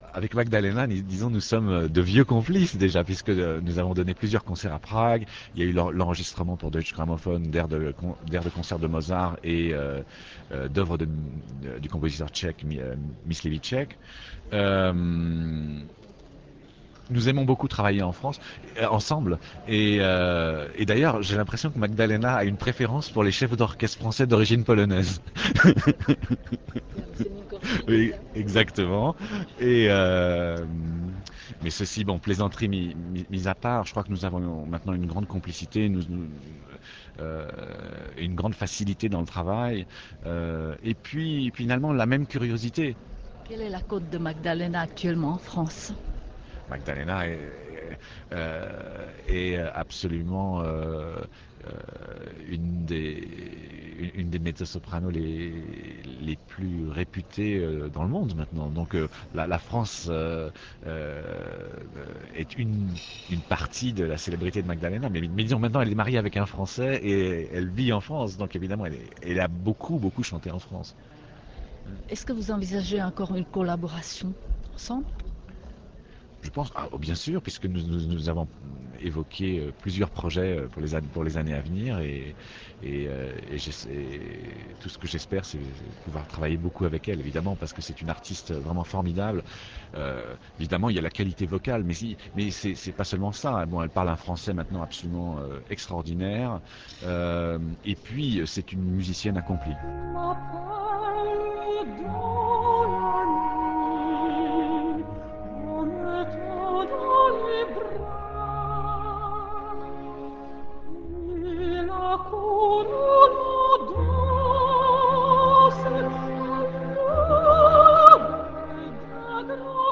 J'espère que le choix des extraits de quelques interviews intéressantes que mes collègues ont réalisées au cours de cette année fera plaisir à vous tous qui êtes actuellement à l'écoute.